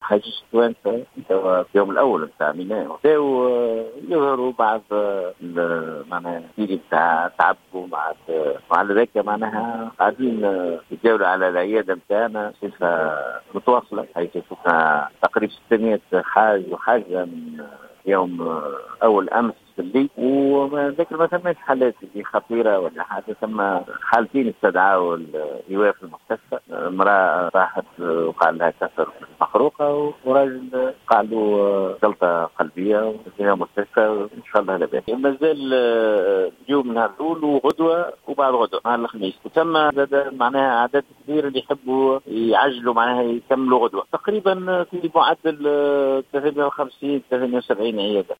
في اتصال هاتفي من البقاع المقدسة